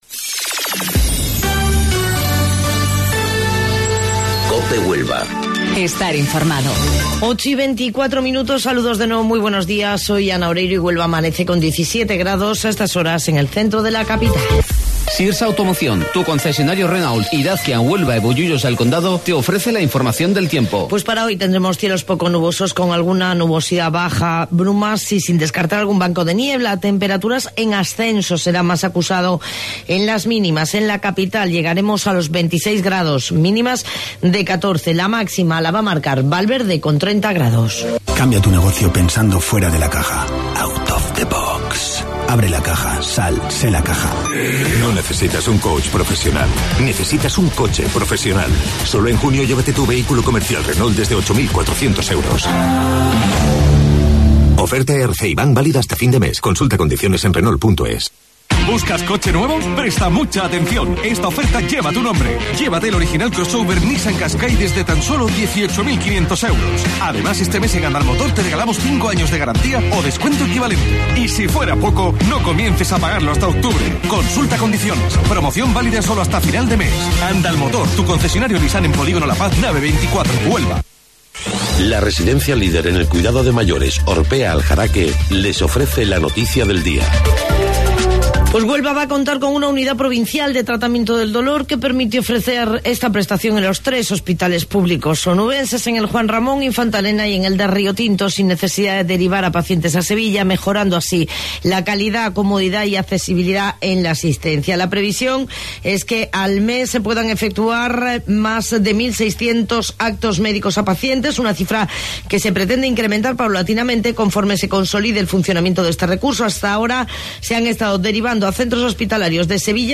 AUDIO: Informativo Local 08:25 del 19 de Junio